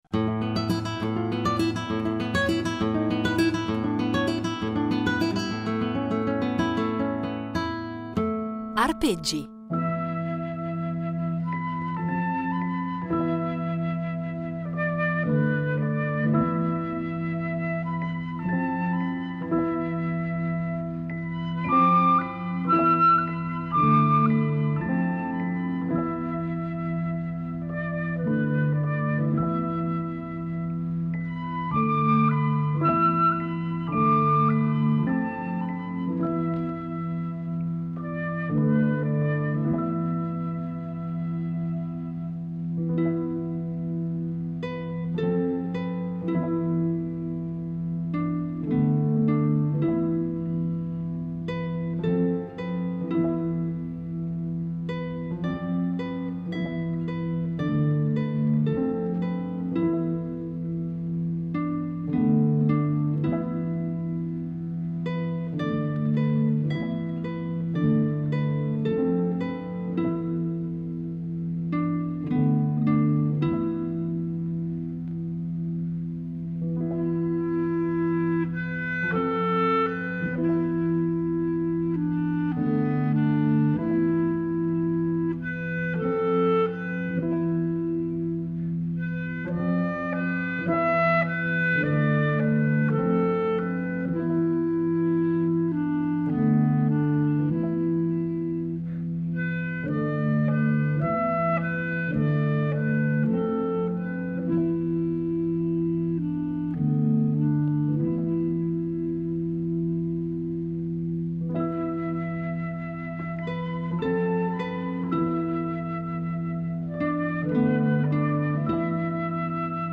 chitarra
cantante
polistrumentista